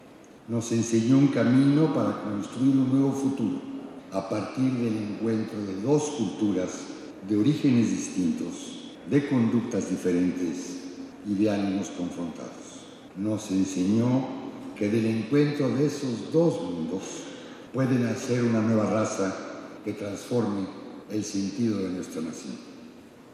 En el Palacio de Bellas Artes, señaló que su partida es como la de un verdadero artista o toltécatl, que de acuerdo con la tesis doctoral del también filólogo y filósofo, eran los sabios nativos, aquéllos que componen cosas, obran hábilmente, crean, hacen todo lo bueno y hermoso.